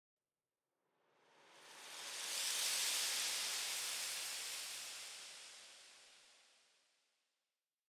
Minecraft Version Minecraft Version latest Latest Release | Latest Snapshot latest / assets / minecraft / sounds / ambient / nether / soulsand_valley / sand3.ogg Compare With Compare With Latest Release | Latest Snapshot
sand3.ogg